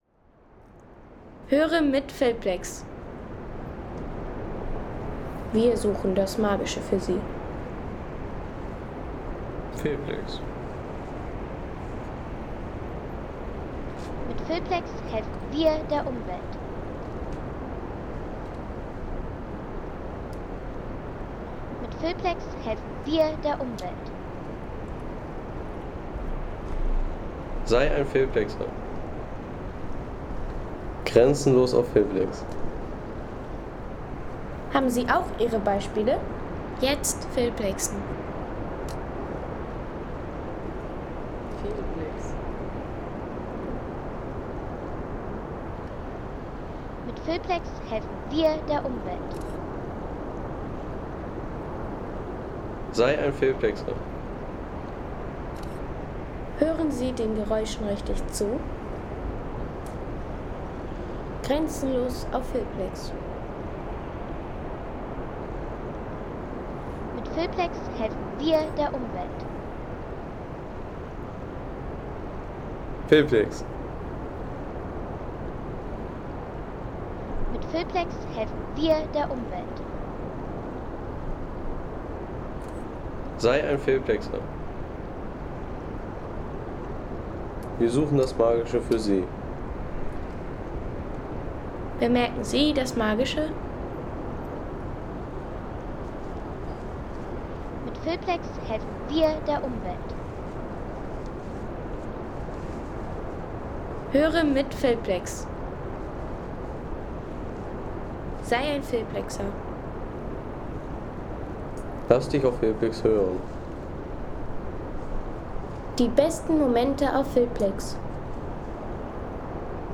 Naturklänge des Gschlößtals: Wind und Wasserfälle am Holzbodenwald ... 3,50 € Inkl. 19% MwSt.